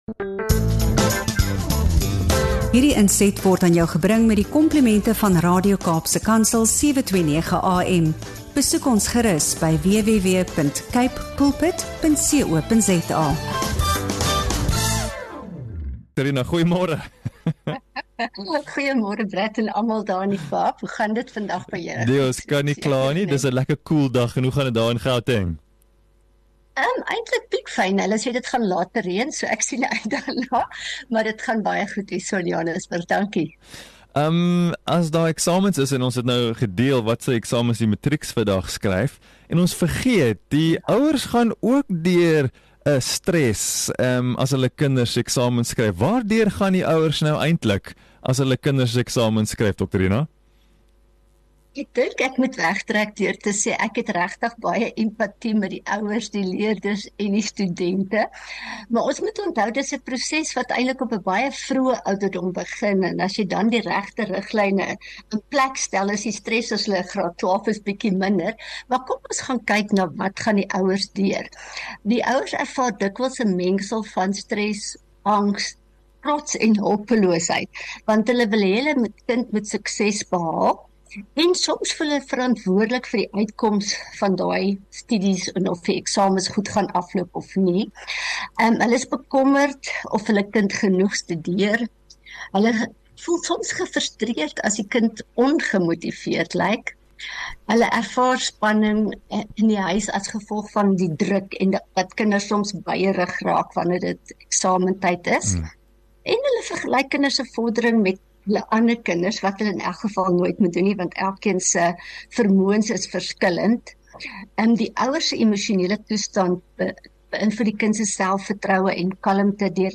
Hierdie gesprek op Radio Kaapse Kansel help ouers om die balans te vind tussen betrokkenheid en vertroue – en herinner dat elke kind se reis uniek is.